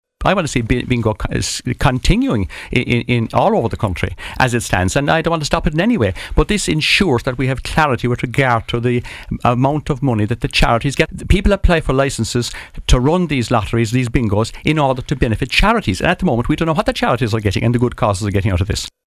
Minister of State at the Department of Justice, David Stanton, insists he doesn’t want to see bingo halls close: